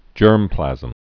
(jûrmplăzəm)